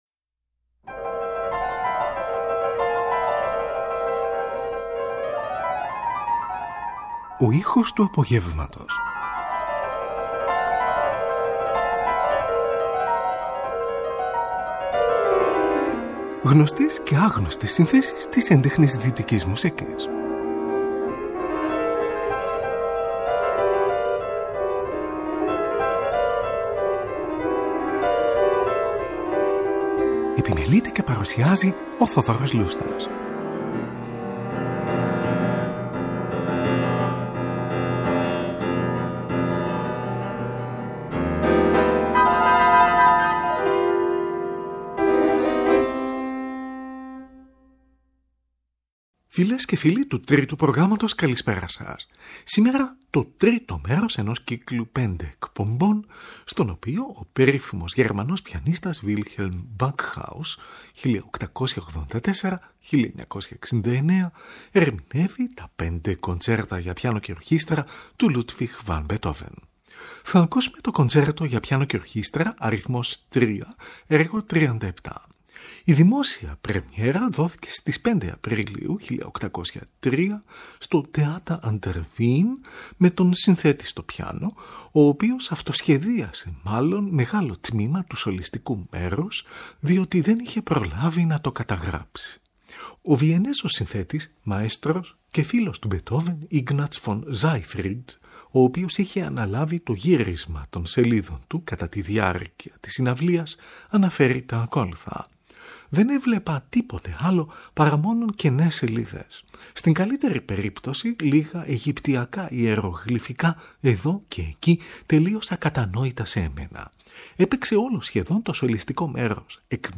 O Πιανίστας Wilhelm Backhaus Ερμηνεύει τα 5 Κοντσέρτα για Πιάνο και Ορχήστρα & Σονάτες του Beethoven – 3o Μέρος | Δευτέρα 09 Δεκεμβρίου 2024